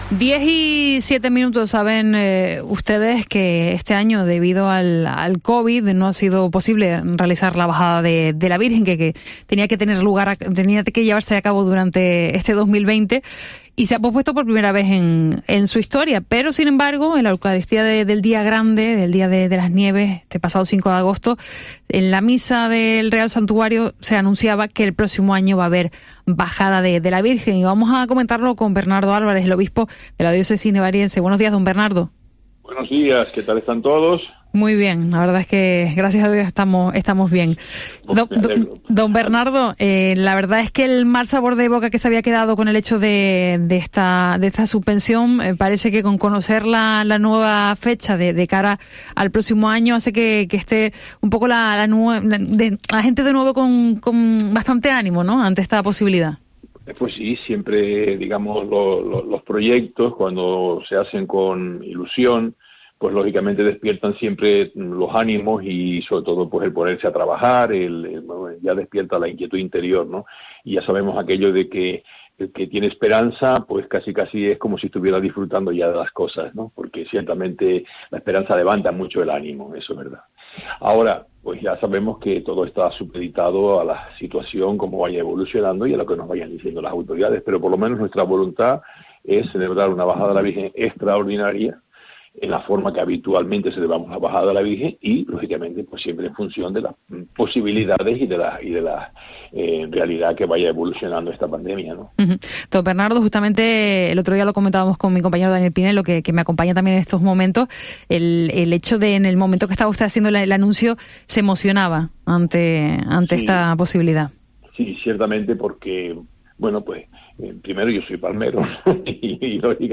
Entrevista al obispo nivariense sobre la Bajada extraordinaria de la Virgen de las Nieves